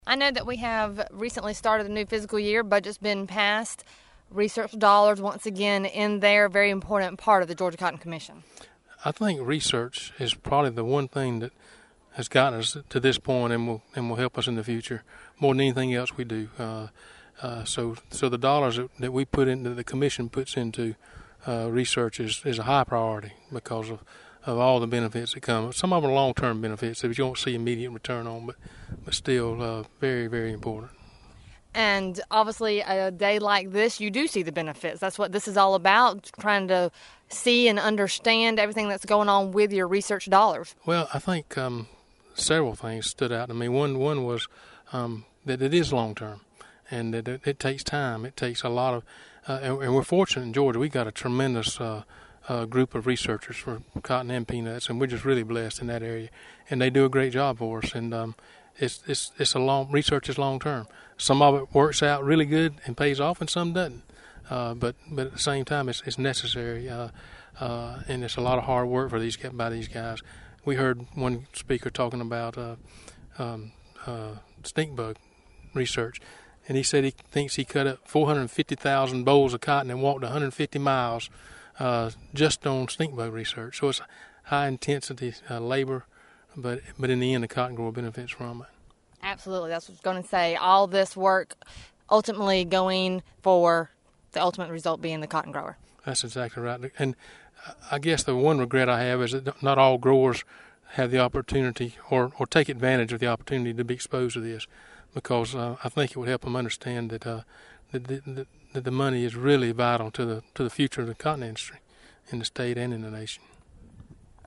UGA Cotton and Peanut Field Day Showcasing Research Efforts - Southeast AgNET